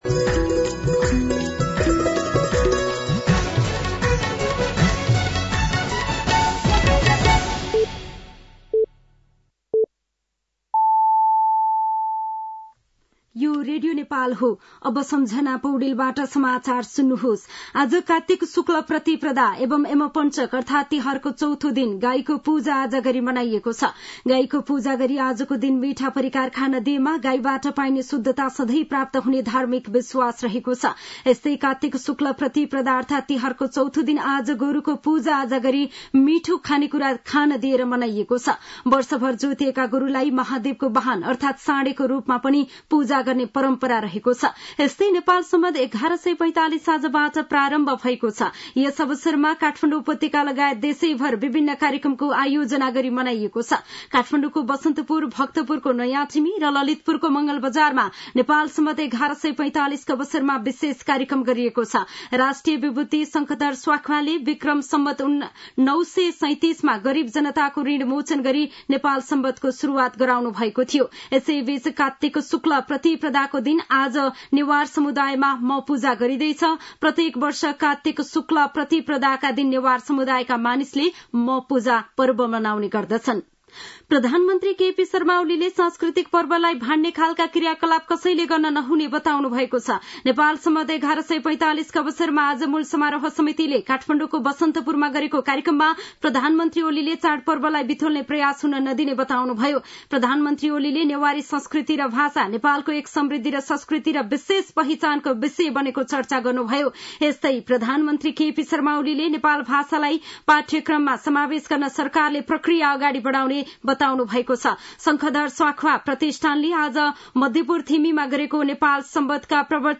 साँझ ५ बजेको नेपाली समाचार : १८ कार्तिक , २०८१